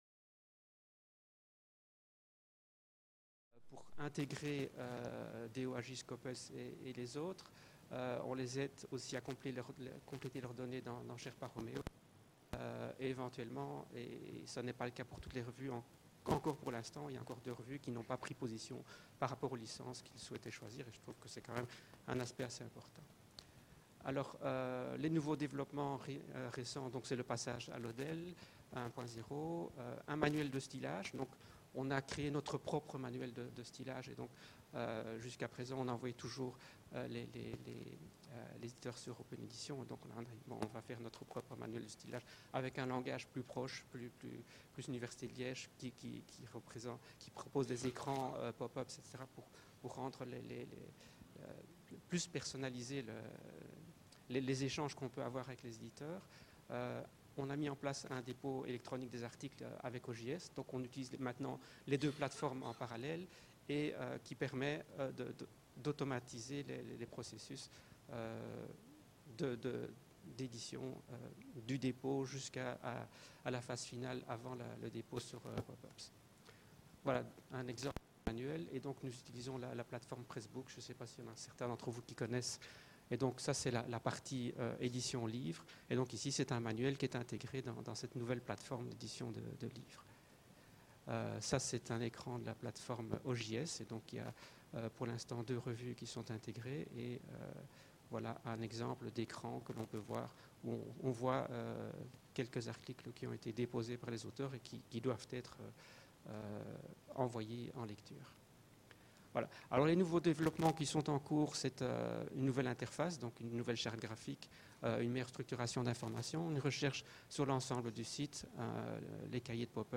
échanges avec la salle